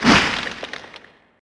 Splat Sound 4